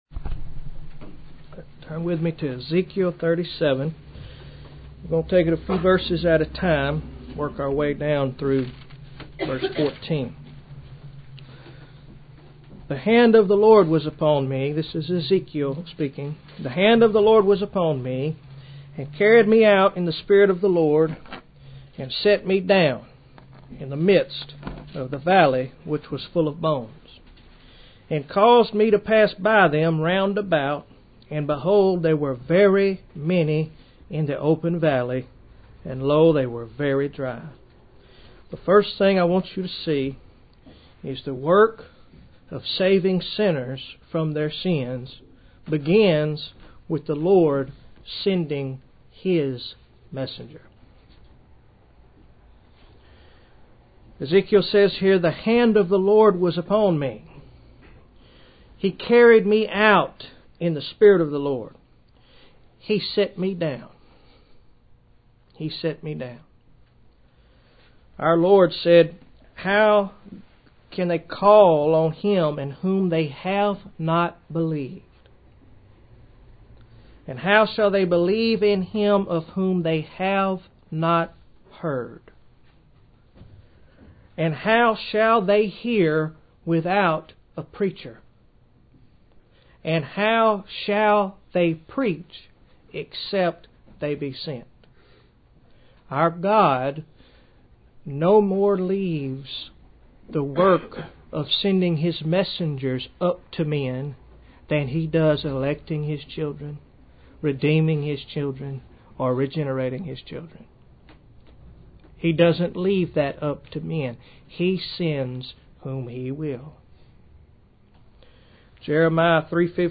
Can Dead Sinners Live? Bible Text Ezekiel 37:1-14 Date 21-Mar-2010 Article Type Sermon Notes PDF Format pdf Word Format doc Audio HI-FI Listen: Can Dead Sinners Live?